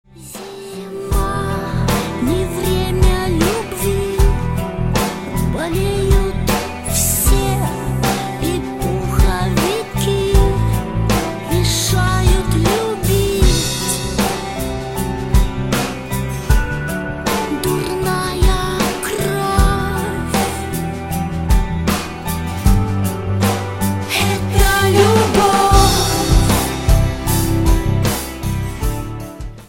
• Качество: 320, Stereo
спокойные